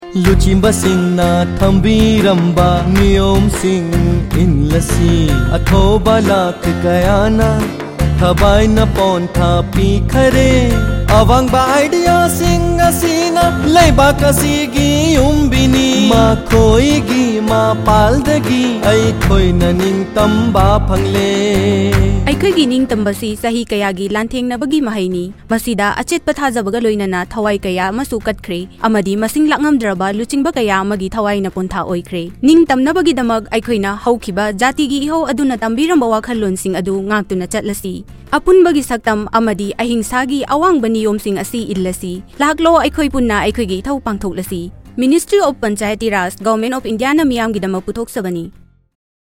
136 Fundamental Duty 2nd Fundamental Duty Follow ideals of the freedom struggle Radio Jingle Manipuri